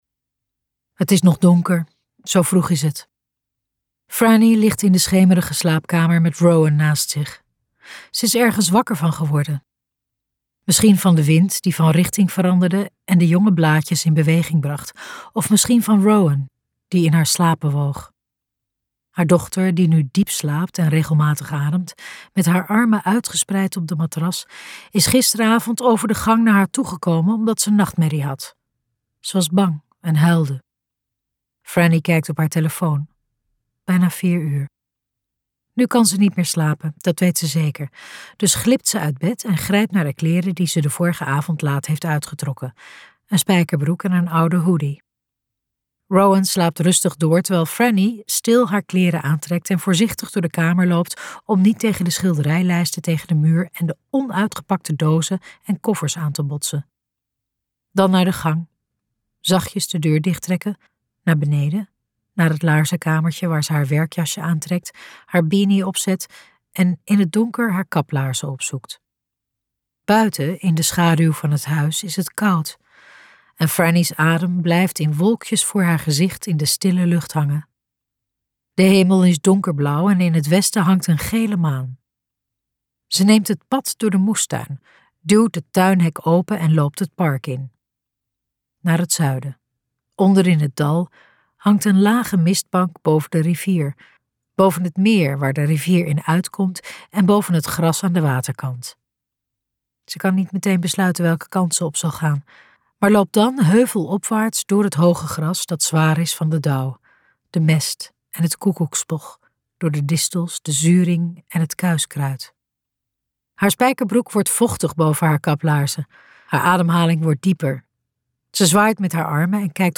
Ambo|Anthos uitgevers - De erfgenamen luisterboek